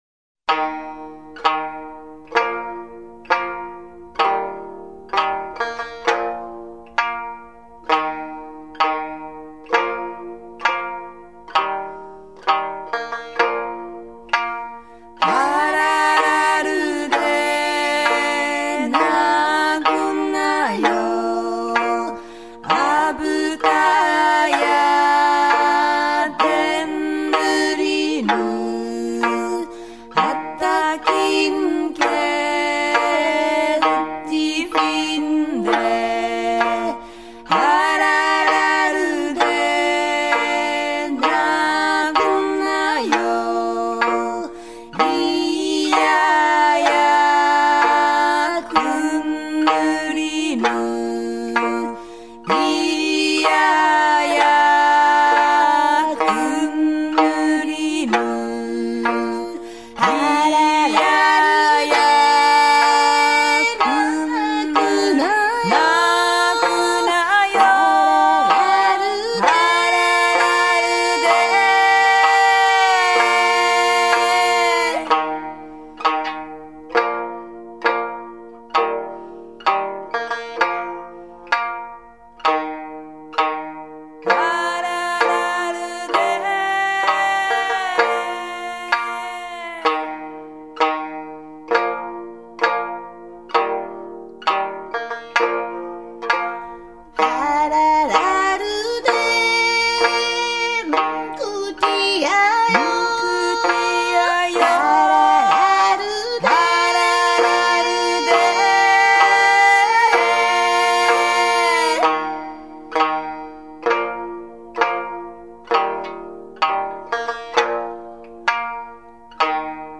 音乐类型：世界音乐
日本 / 冲绳、台湾、太平洋群岛民歌